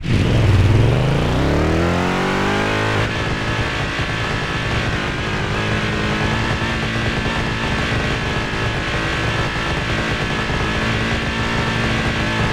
Index of /server/sound/vehicles/sgmcars/buggy
rev.wav